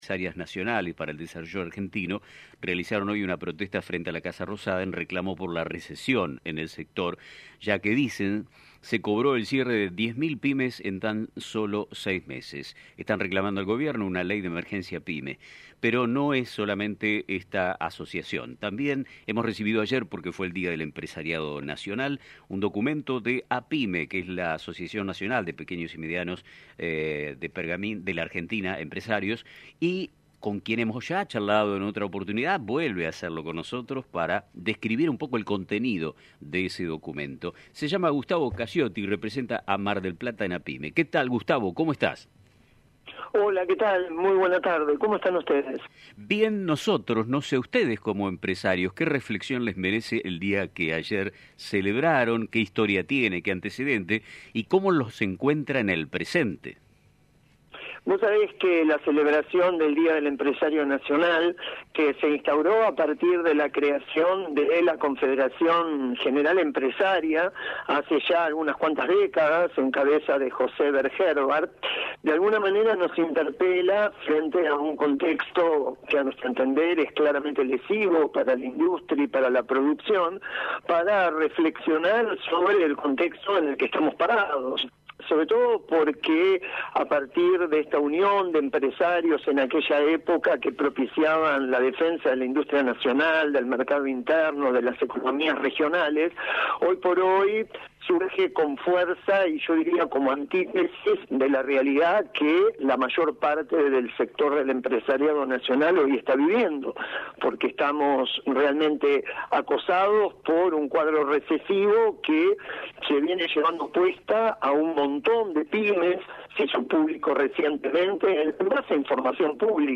En el marco del Día del Empresariado Nacional, la Asociación de Pequeños y Medianos Empresarios (APYME) realizó una contundente denuncia sobre la alarmante situación que atraviesan las pymes en Argentina. En una entrevista reciente con Radio Mon Pergamino